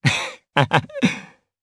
Kibera-Vox_Happy1_jp.wav